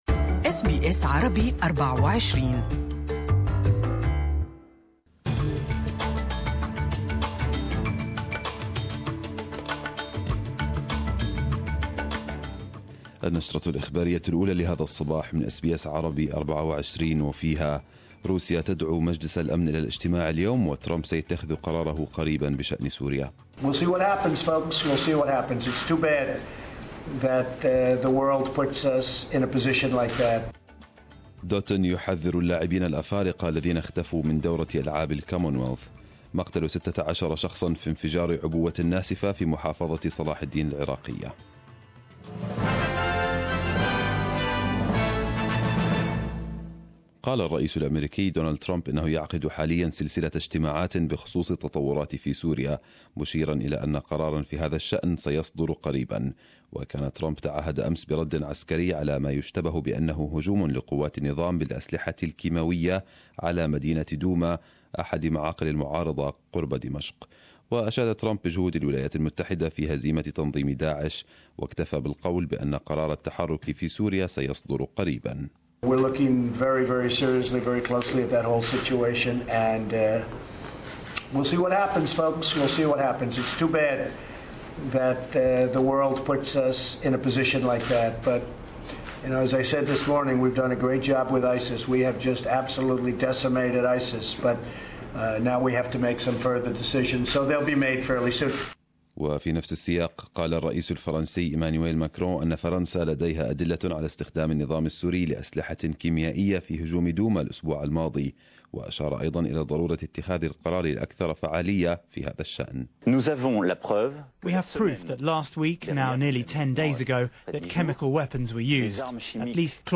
Arabic news Bulletin 13/04/2018